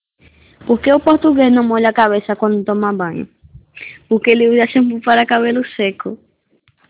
Si? Pois semella que o teu coñecemento do portugués non é nulo...as gravacións son de persoas portuguesas e brasileiras, achas algunha diferenza?